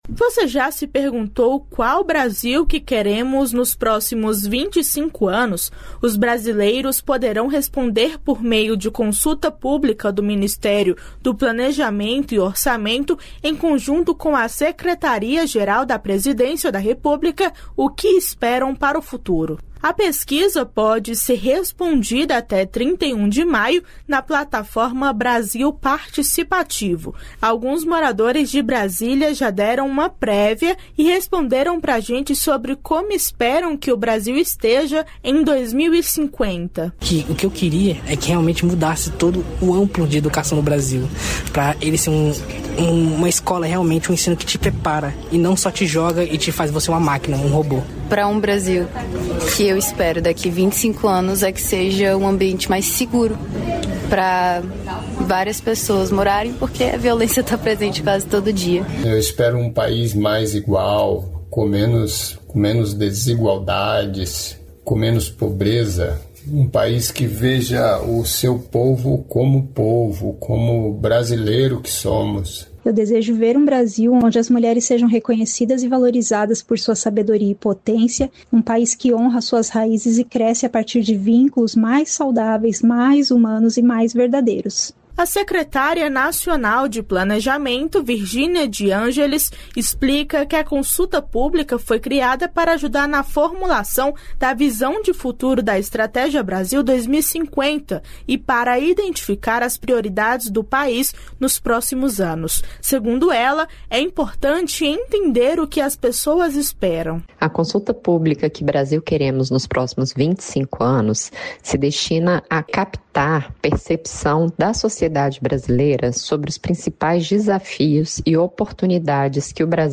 Repórter Rádio Nacional